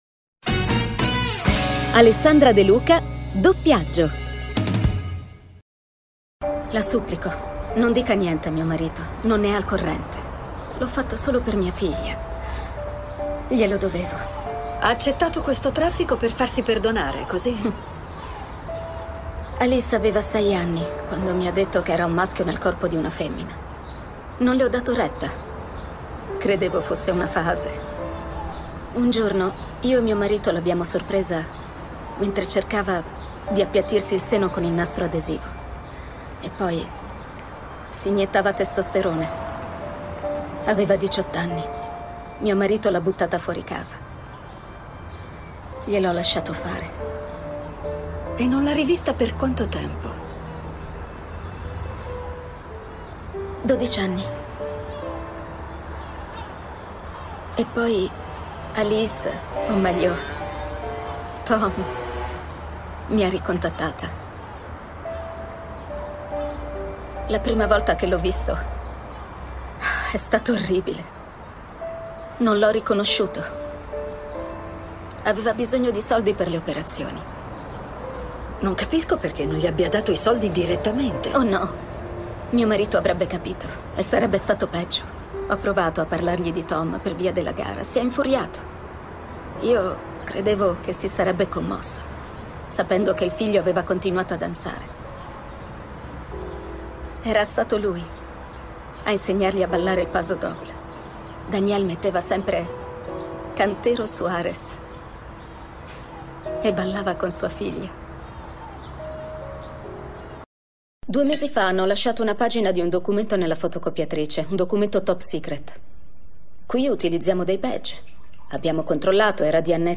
Il mondo dei doppiatori
voceadl.mp3